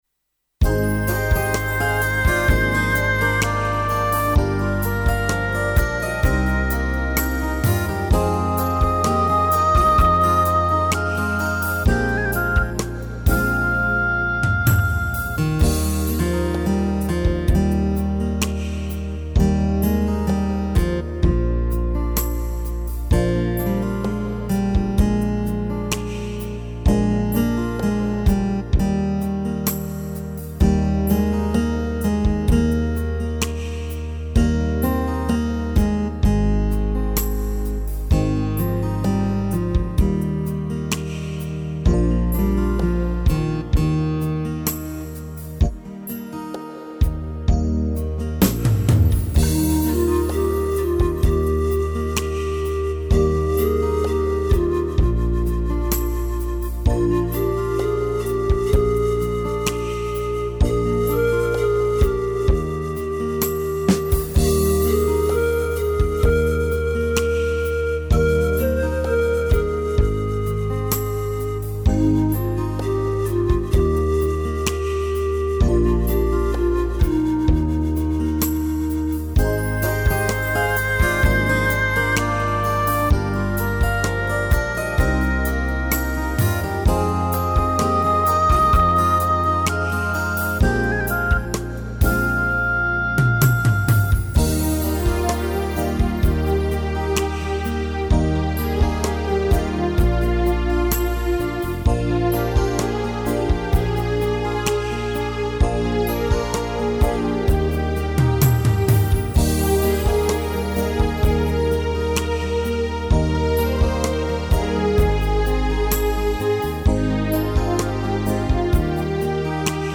Compositeur interprète, travaille sur Yamaha, avec synthé, séquenceur 2x16 pistes et studio de mixage - Mastering Audio.